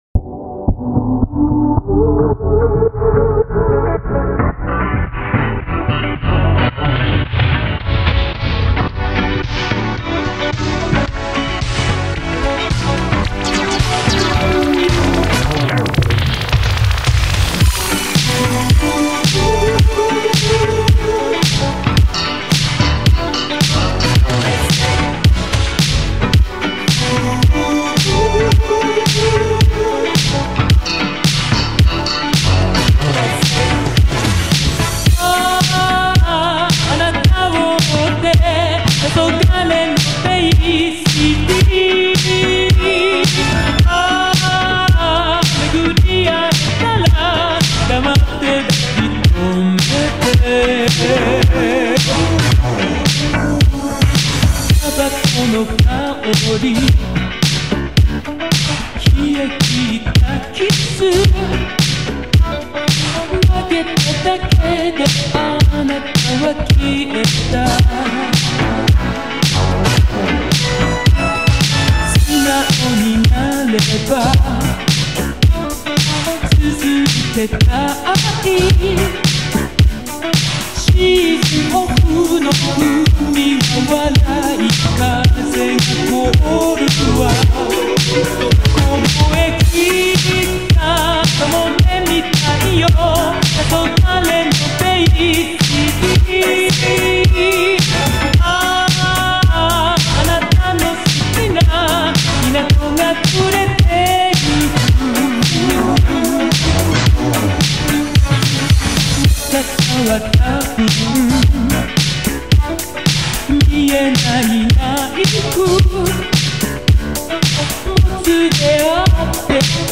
نسخه VAPORWAVE